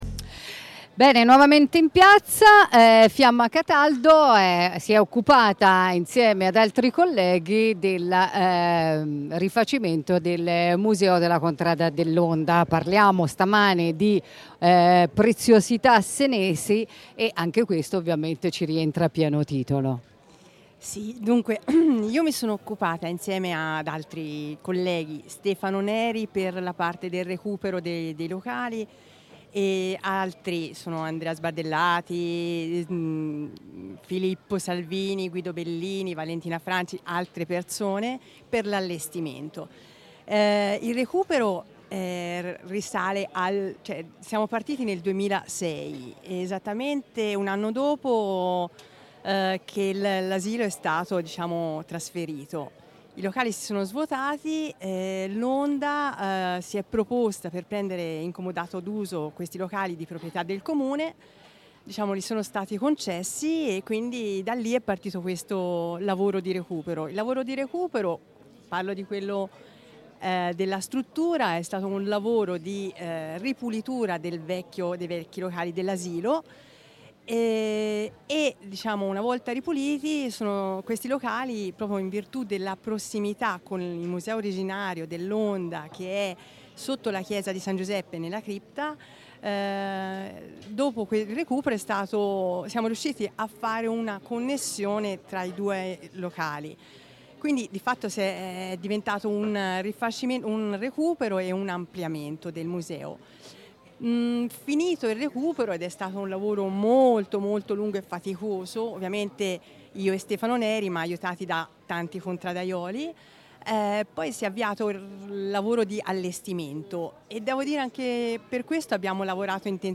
In diretta dai palchi